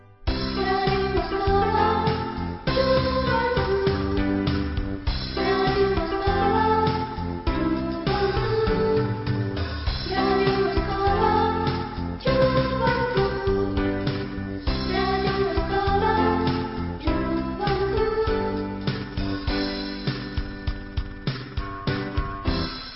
Indicatiu cantat de l'emissora.